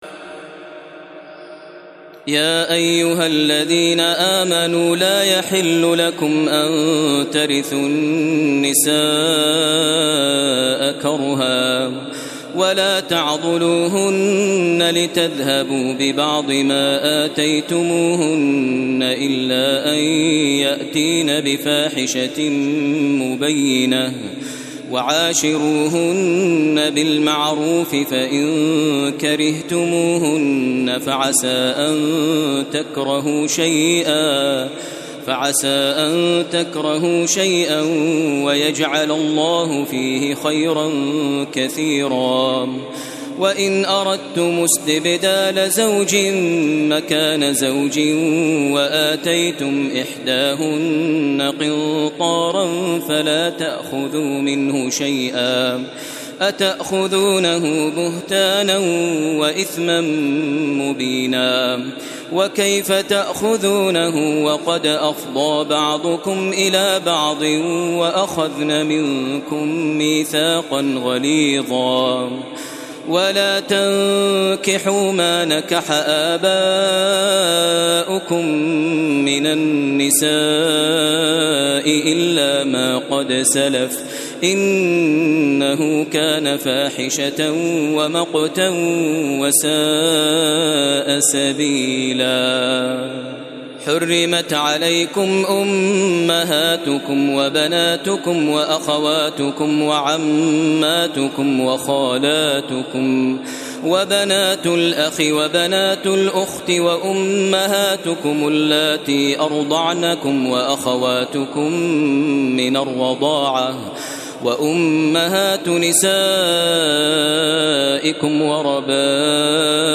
تراويح الليلة الرابعة رمضان 1432هـ من سورة النساء (19-87) Taraweeh 4 st night Ramadan 1432H from Surah An-Nisaa > تراويح الحرم المكي عام 1432 🕋 > التراويح - تلاوات الحرمين